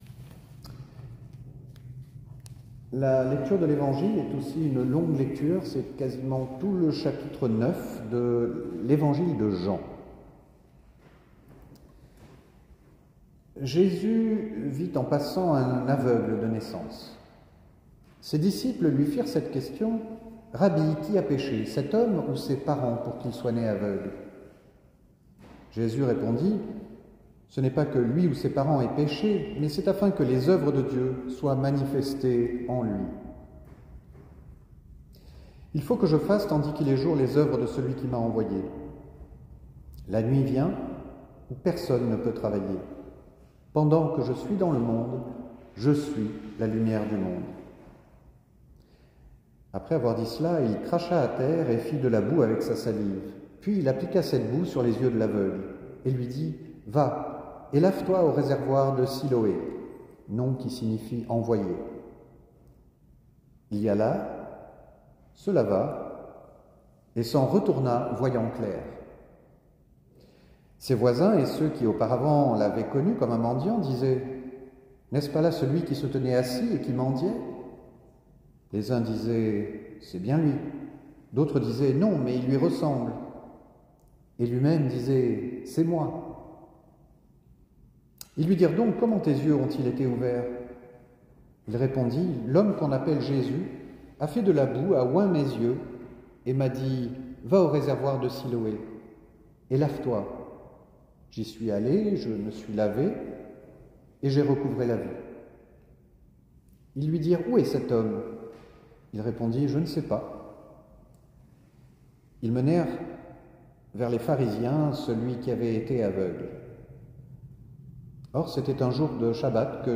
Culte du 19 mars 2023